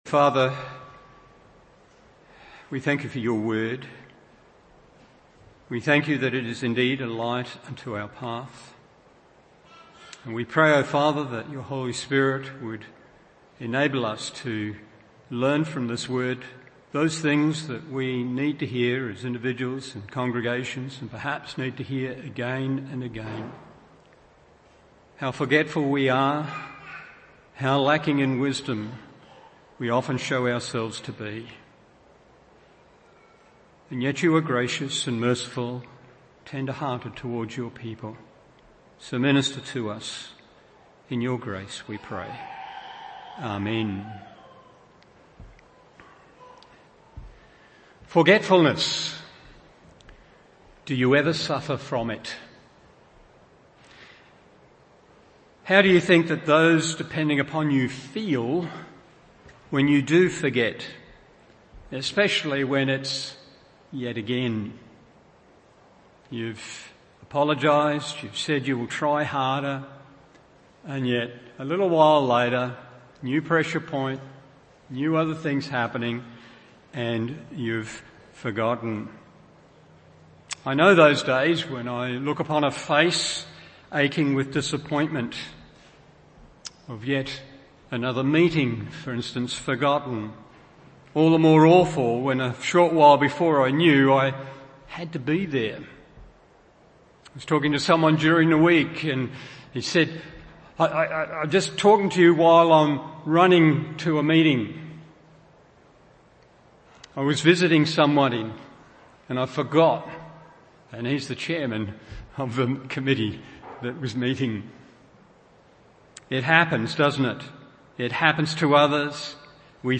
Morning Service Genesis 8:1-5 1.